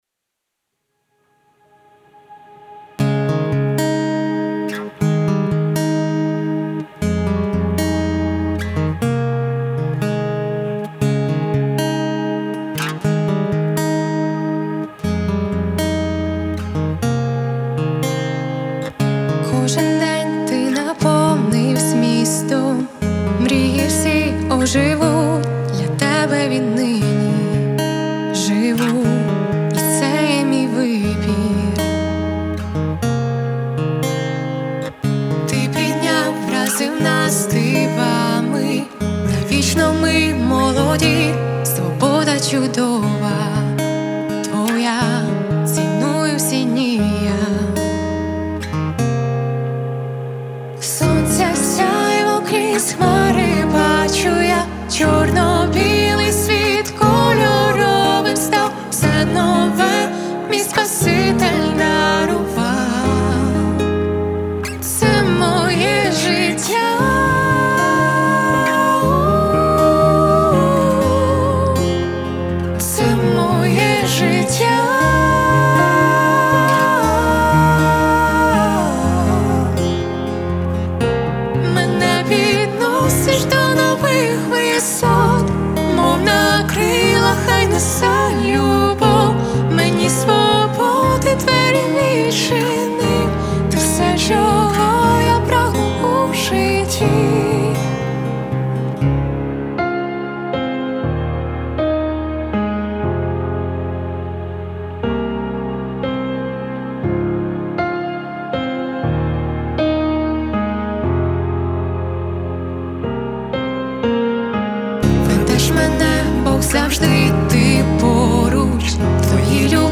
173 просмотра 181 прослушиваний 17 скачиваний BPM: 60